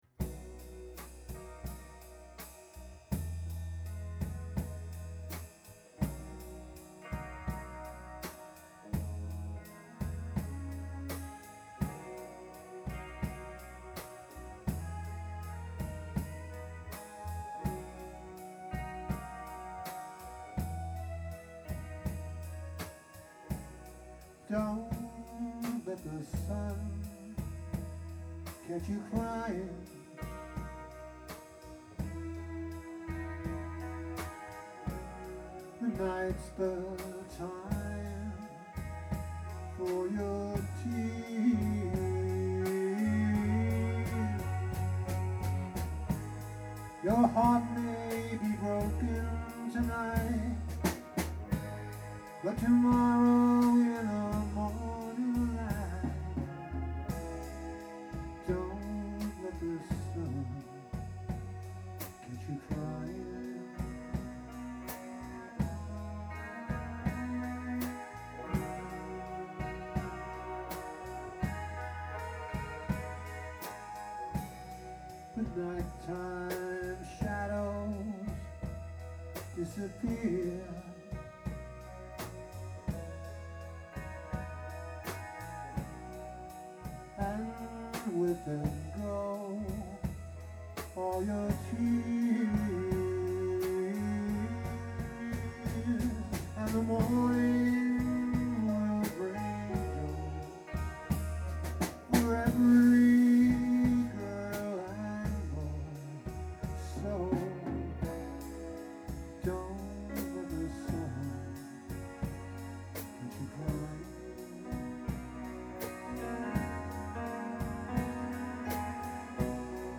lead vocals & percussion
drums and vocals
keyboards and lead vocals
guitars and lead vocals